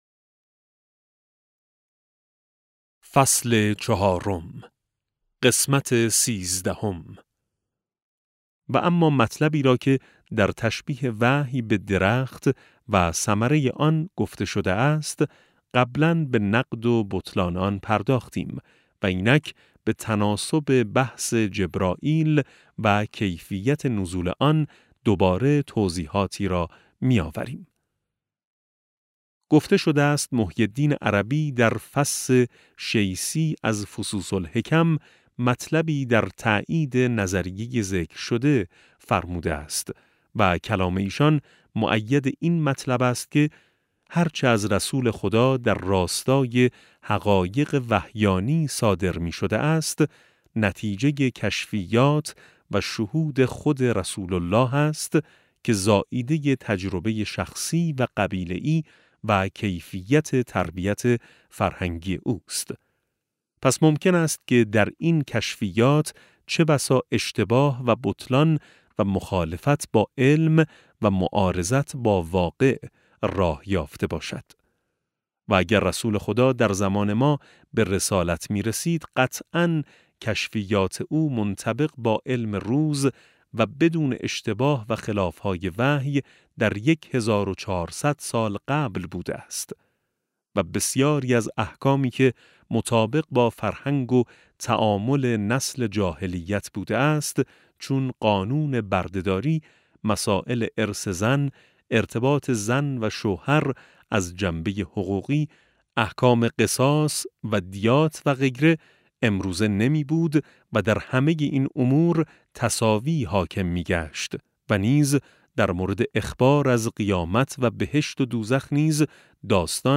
کتاب صوتی افق وحی